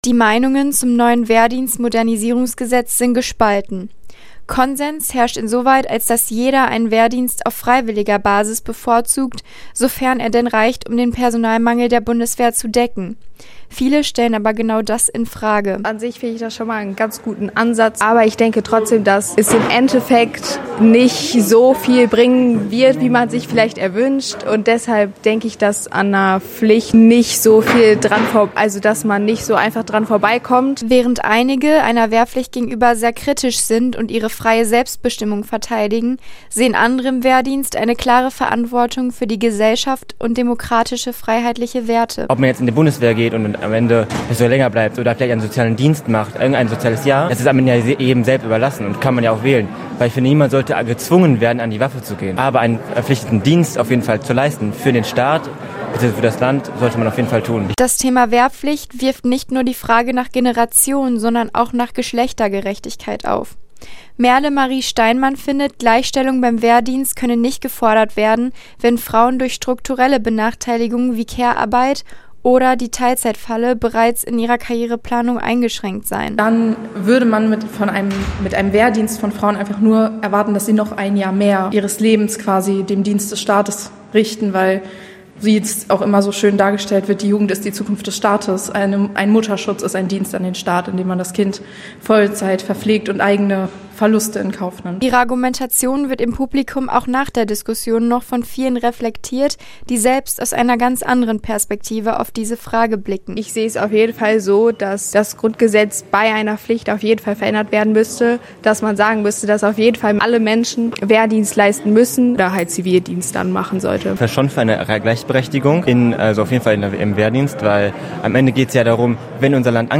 Sollte eine Wehrpflicht dann auch für Frauen gelten? Diese Fragen wurden gestern Abend im Rahmen des politischen Salons im Kinder- und Jugendtheater LUTZ von Jugendlichen aus politischen Verbänden in Hagen diskutiert.
beitrag-zum-politischen-salon.mp3